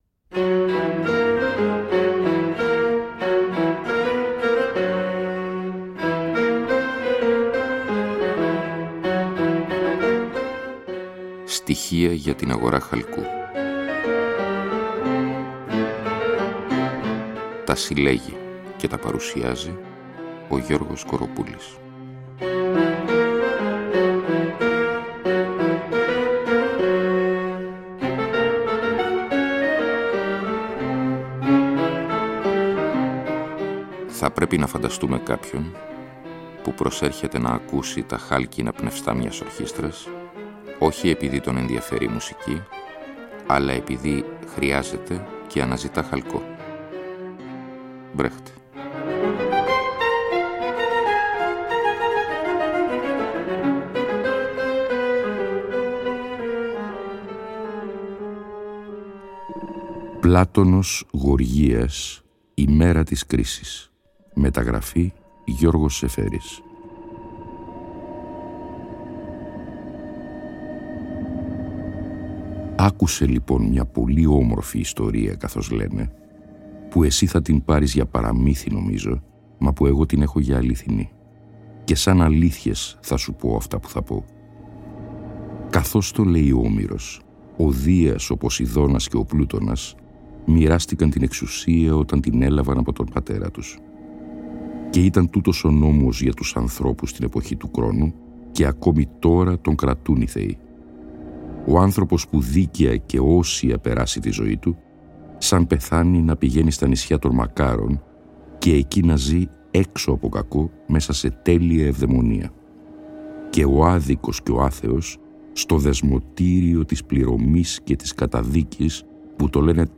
Εκπομπή λόγου.